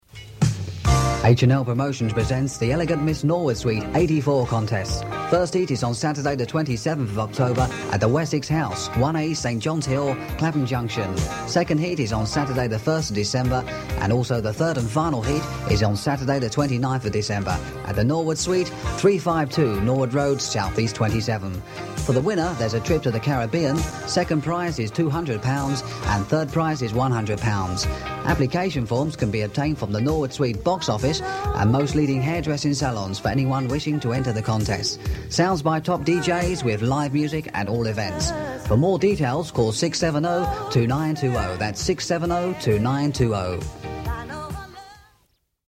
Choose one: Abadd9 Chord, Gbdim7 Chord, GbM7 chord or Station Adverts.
Station Adverts